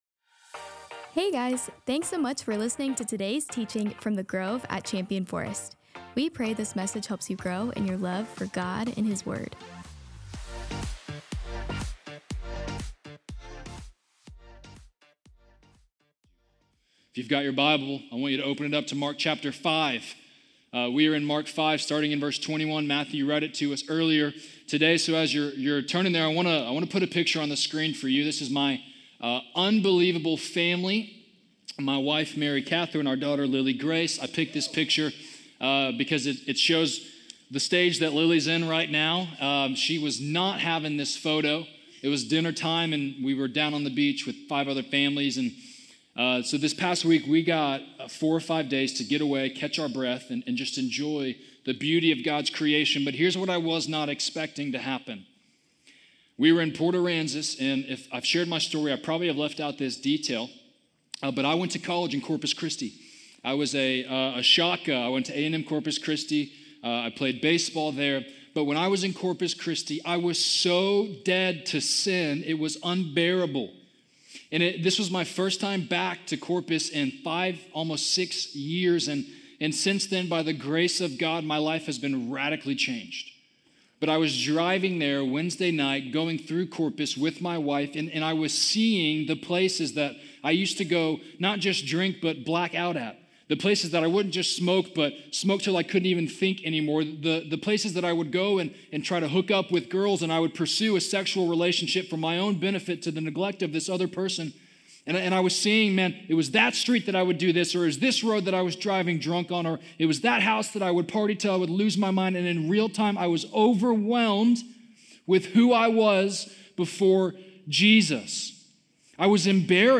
Wednesday Sermons – Media Player